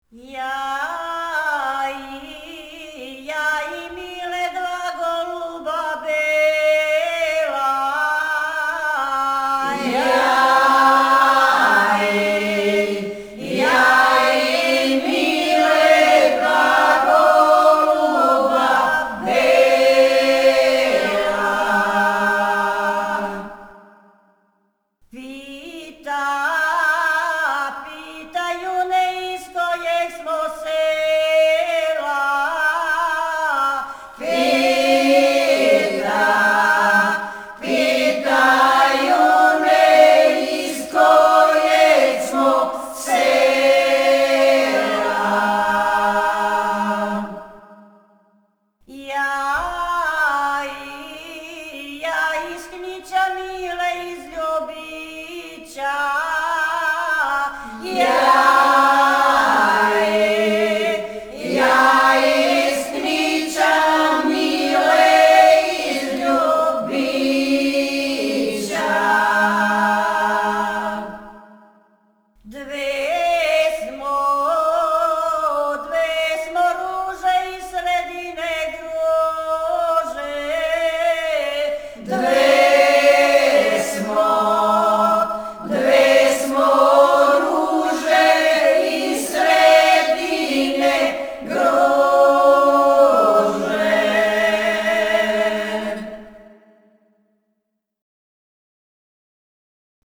Снимци Група "Стеван Книћанин", Кнић (3.1 MB, mp3) О извођачу Ја и Миле два голуба бела Питају ме из којег смо села Ја из Кнића Миле из Добрића Две смо из руже из средине Груже Порекло песме: Шумадија Начин певања: ?